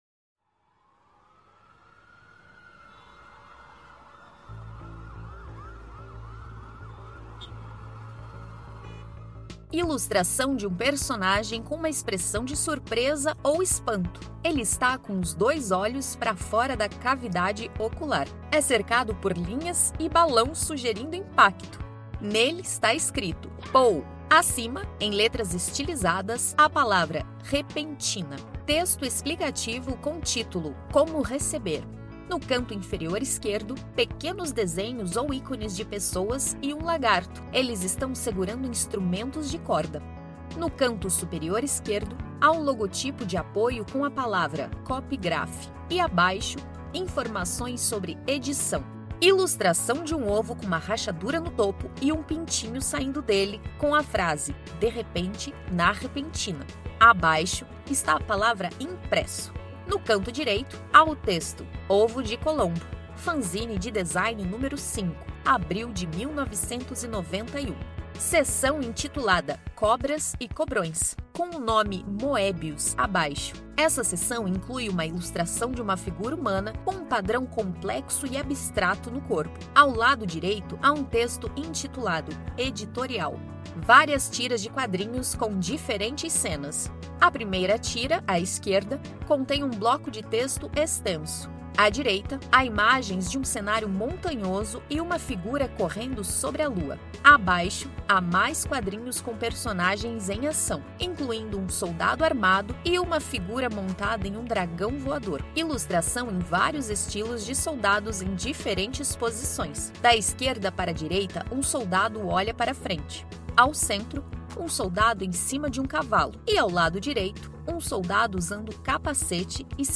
Audiodescrição do Fanzine n° 5